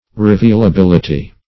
Search Result for " revealability" : The Collaborative International Dictionary of English v.0.48: Revealability \Re*veal`a*bil"i*ty\, n. The quality or state of being revealable; revealableness.